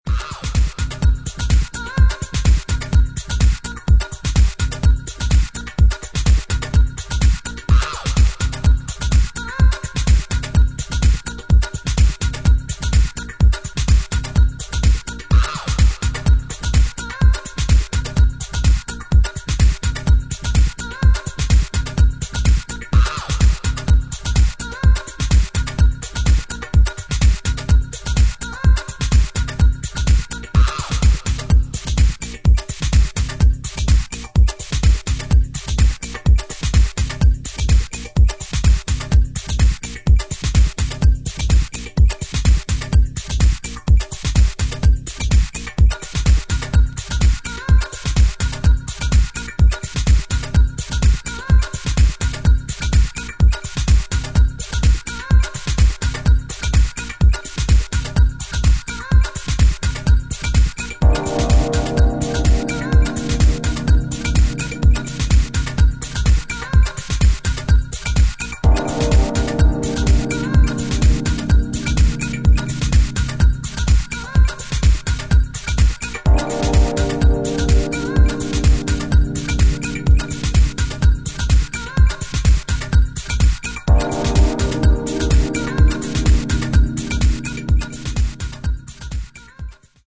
低音の効いたトライバルでミニマル感のあるグルーヴに、ヴォイス・サンプルや西海岸らしいムードのパッド・シンセで展開していく